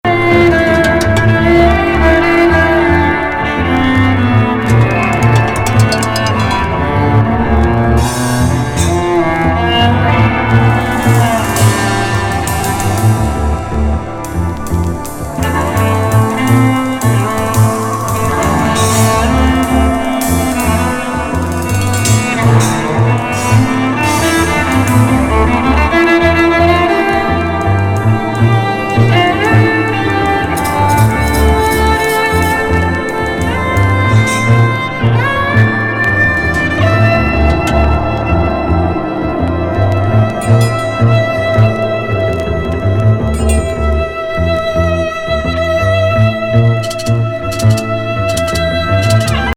密室エスニック・ジャズ!